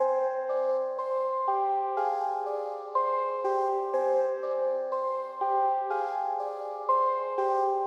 描述：阴影的钢琴
Tag: 122 bpm Trap Loops Piano Loops 1.32 MB wav Key : Unknown